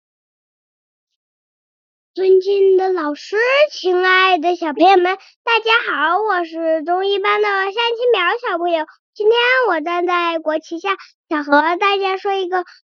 Professionell AI-röst för tal och presentationer
Omvandla dina manus till fängslande röstinspelningar med naturlig kadens och professionell tydlighet för alla typer av publik.
Talsyntes
Professionell berättarröst
Tydlig artikulation